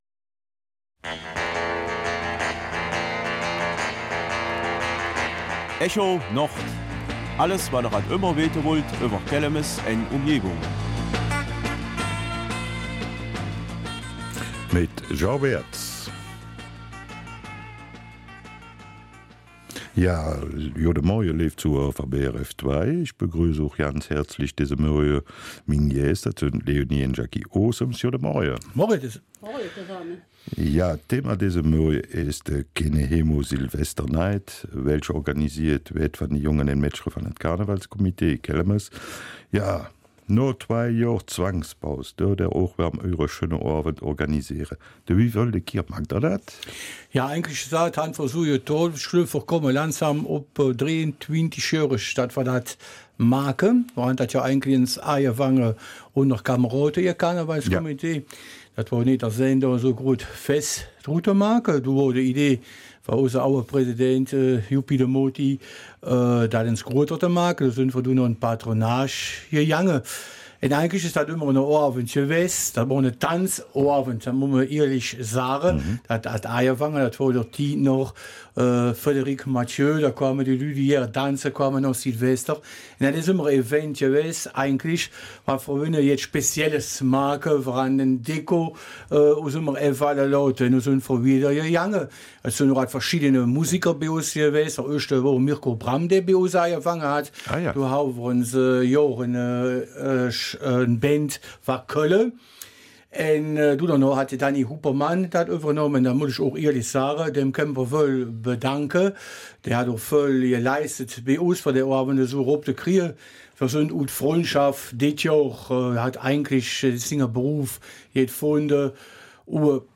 Kelmiser Mundart